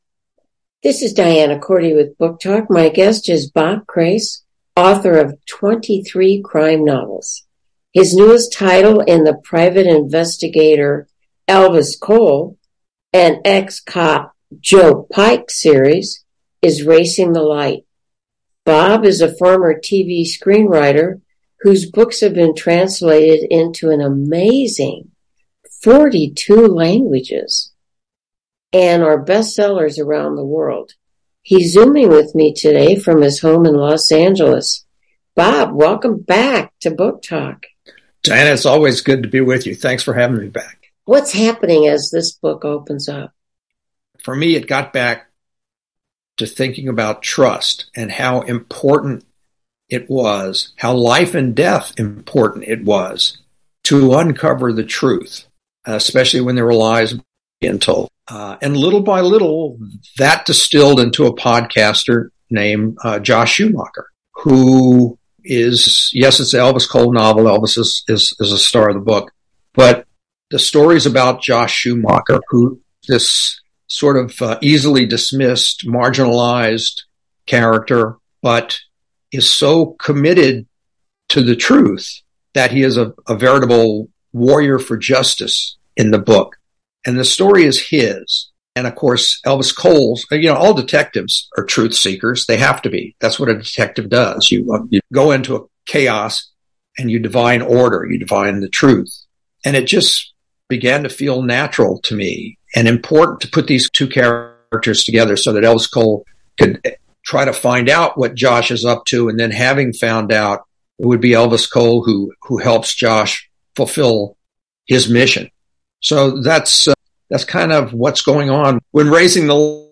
Top authors are interviewed on this 10-minute program that captures their words about their books and ideas and often the story behind the story.